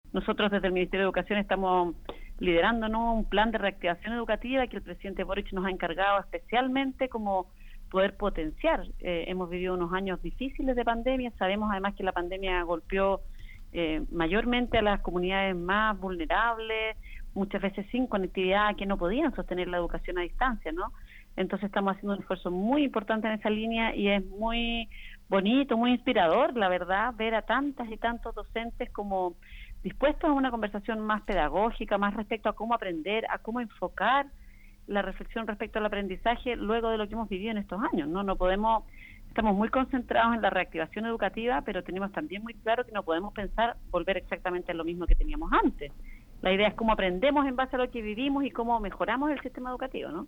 Así lo explicó la Subsecretaría de Educación, Alejandra Arratia quien señaló que se está realizando un esfuerzo para que los estudiantes puedan volver a nivelar los aprendizajes, valorando de esta forma el trabajo de los y las docentes.